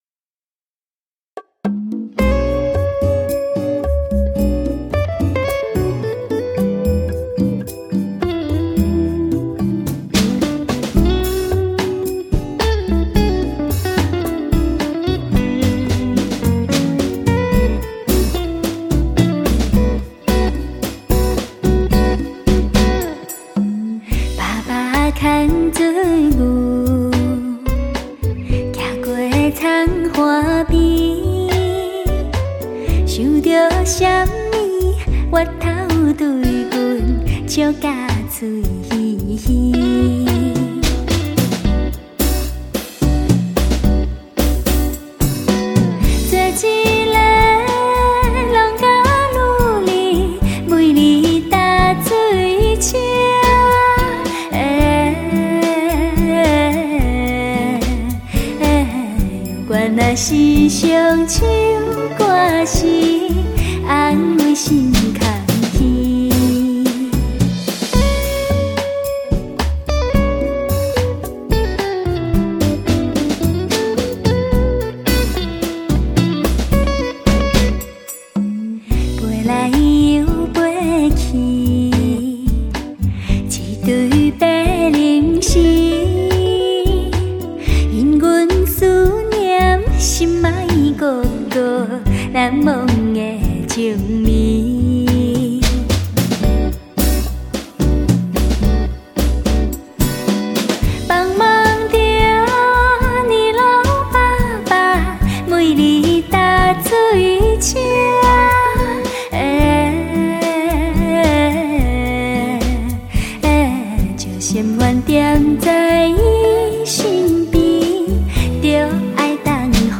独特纯粹闽南曲
携HD技术完美呈现